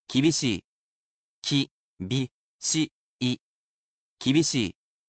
Though he’s a robot, he’s quite skilled at speaking human language. He’s lovely with tones, as well, and he will read each mora so you can spell it properly in kana.